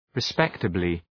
{rı’spektəblı}